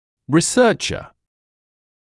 [rɪ’sɜːʧə][ри’сёːчэ]исследователь; ученый, научный работник